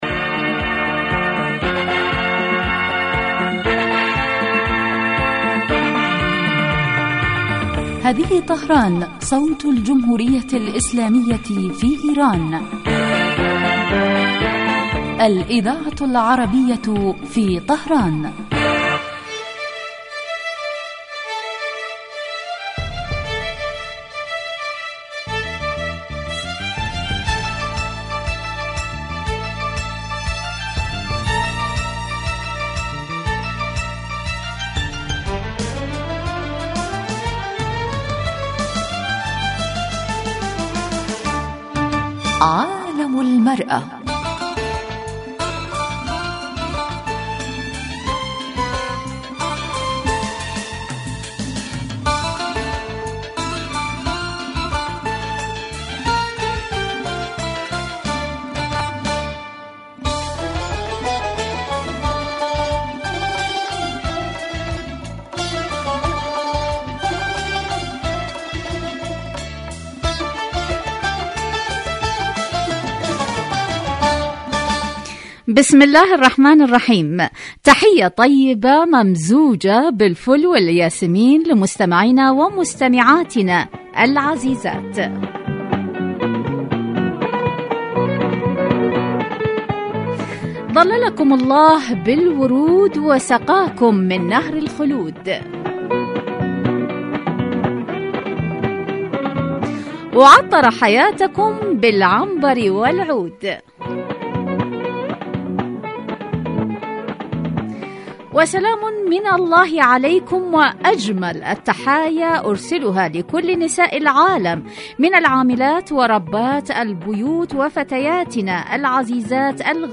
من البرامج الناجحة في دراسة قضایا المرأة فی العالمین الإسلامي و العربي ومعالجة ما لها من مشکلات و توکید دورها الفاعل في تطویر المجتمع في کل الصعد عبر وجهات نظر المتخصصین من الخبراء و أصحاب الرأي مباشرة علی الهواء